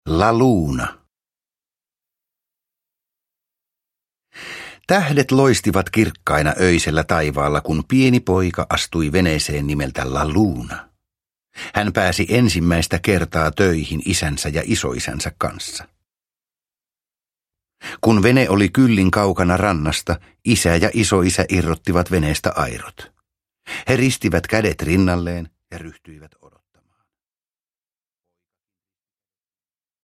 La Luna – Ljudbok – Laddas ner